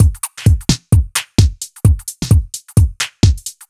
Index of /musicradar/uk-garage-samples/130bpm Lines n Loops/Beats